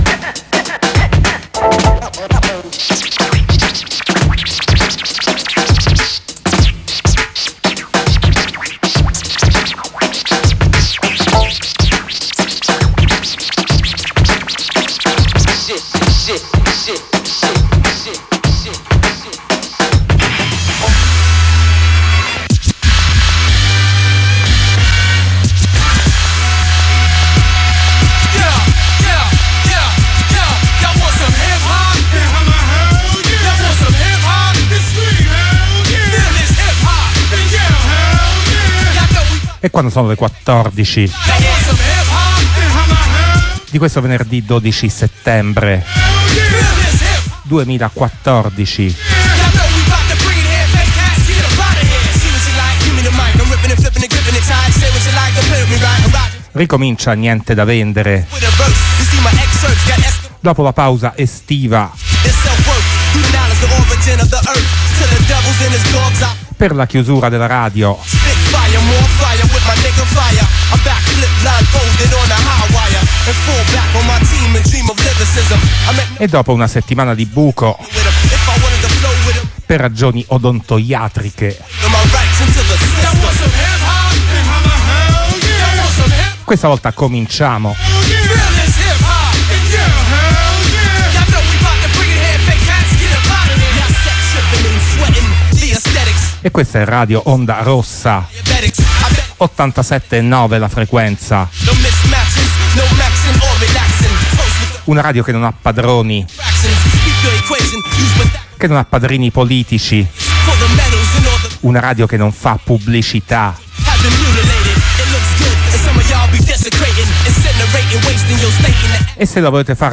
spazio musicale hip hop | Radio Onda Rossa